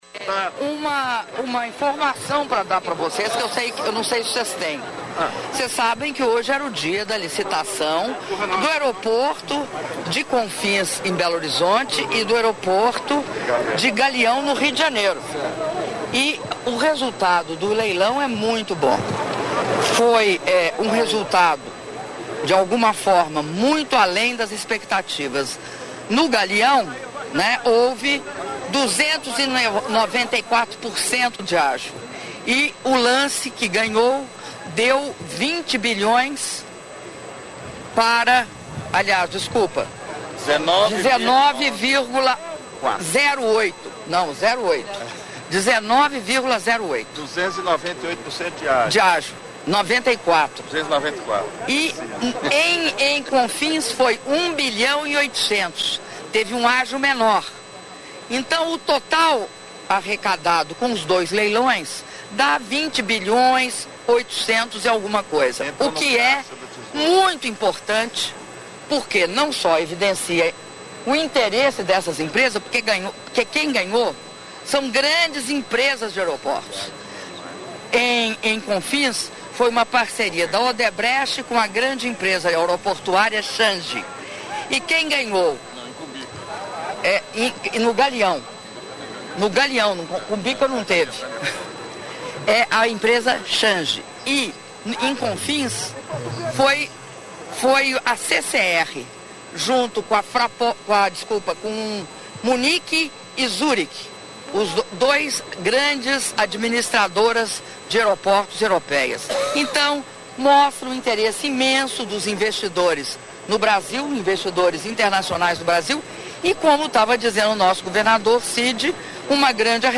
Áudio da entrevista concedida pela Presidenta da República, Dilma Rousseff, antes da cerimônia de anúncio de investimentos do PAC2 Mobilidade Urbana - Fortaleza/CE